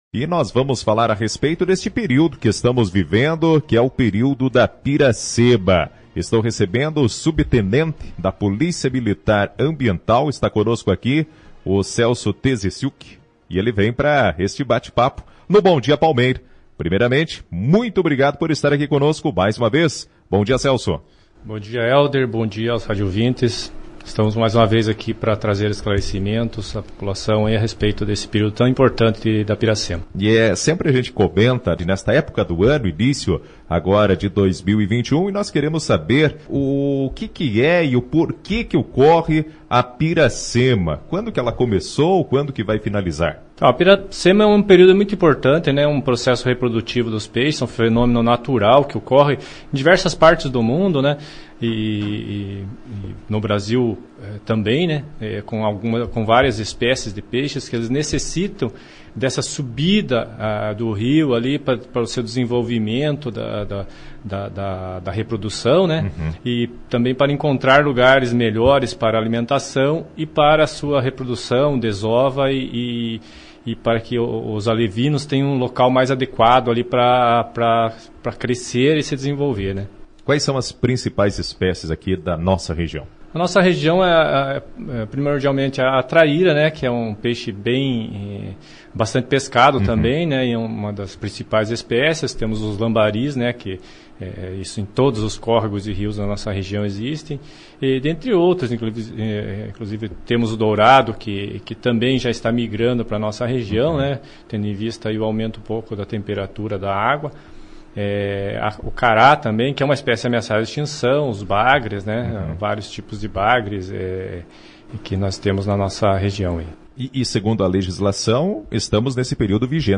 Ouça a entrevista e saiba os canais de comunicação para efetivar denúncias relacionadas ao meio ambiente.